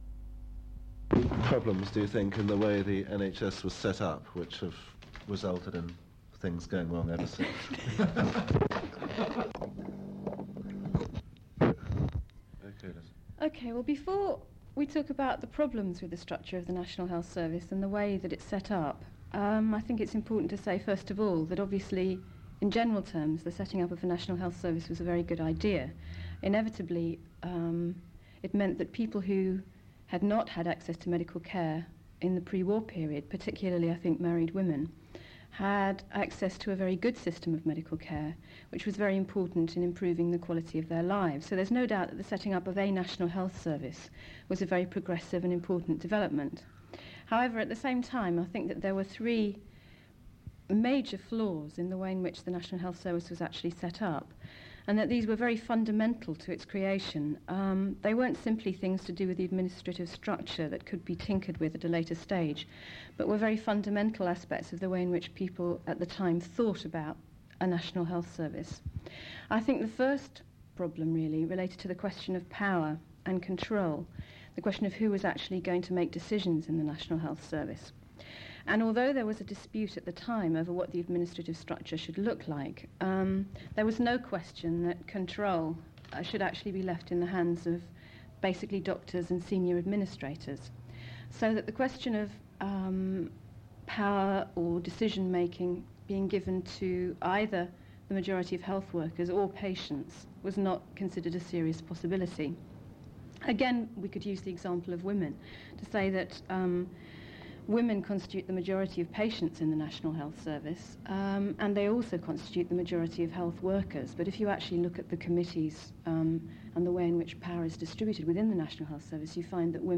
Side 2 of the audio cassette is available to listen online.